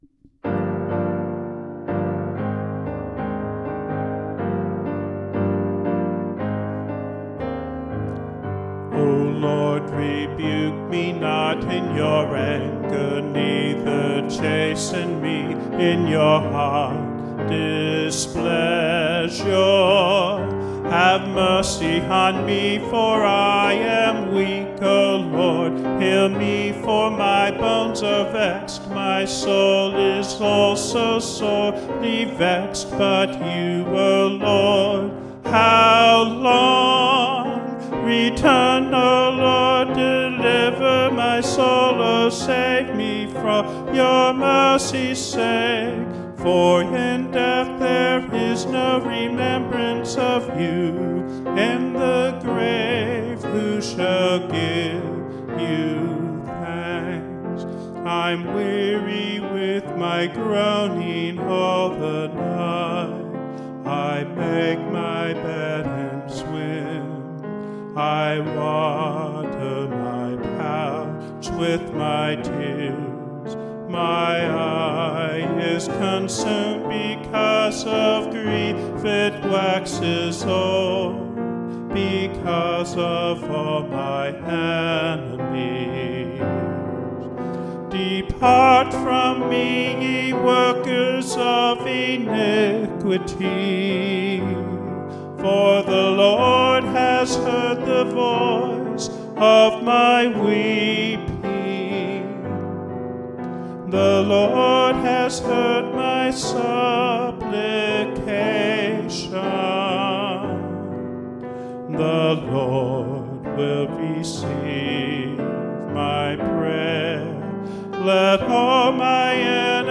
OGG PDF ABC 2026 Early One Take recording